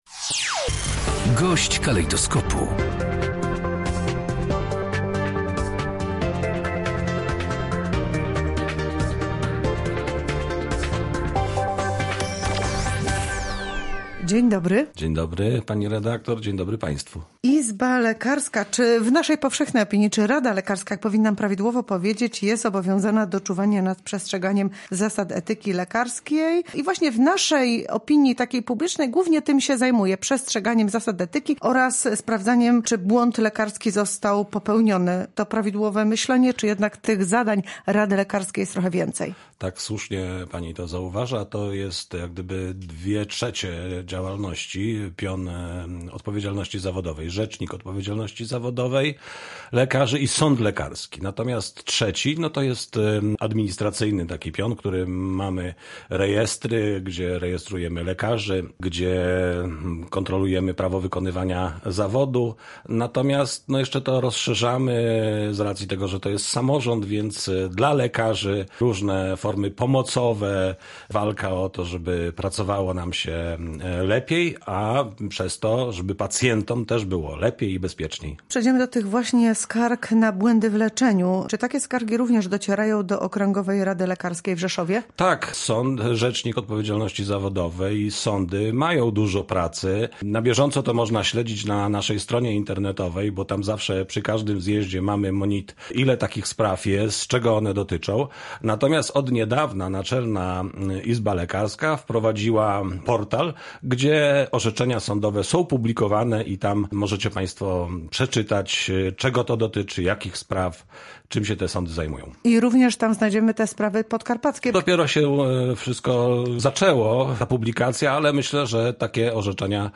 Więcej na ten temat w rozmowie